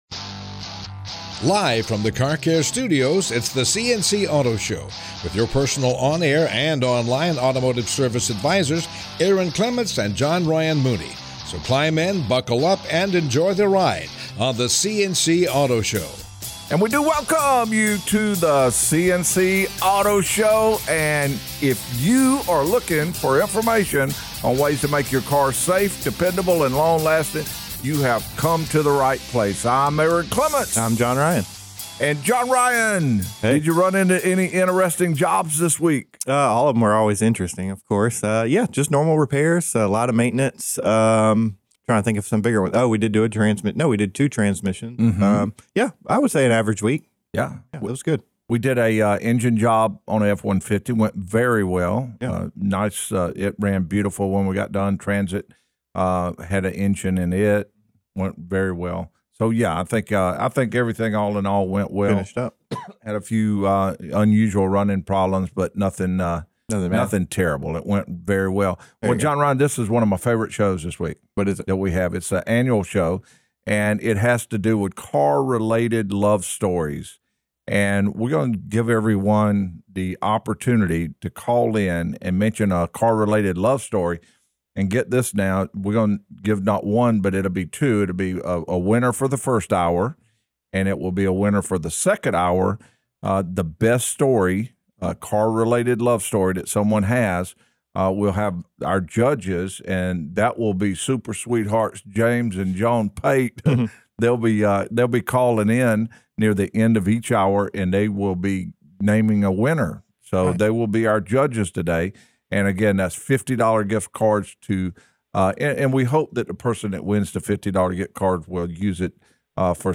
listen to callers calling in with their Automotive Love Stories